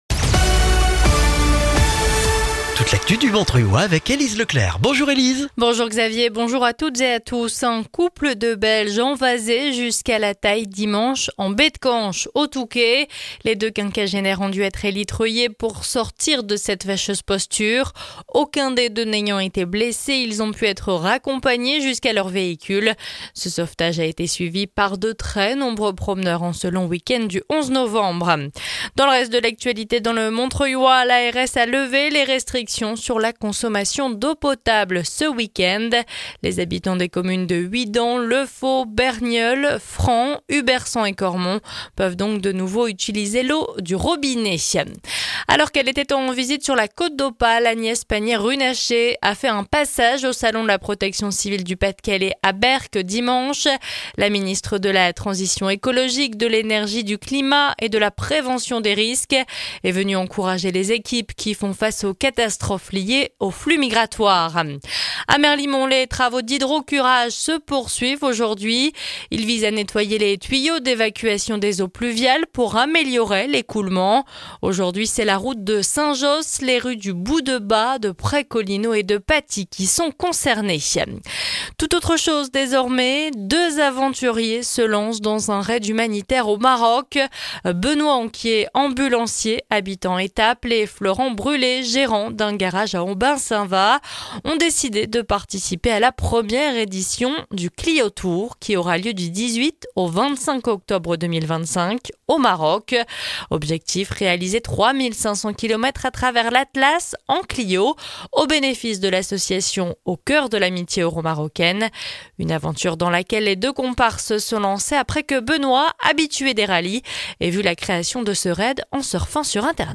Le journal du mardi 12 novembre dans le Montreuillois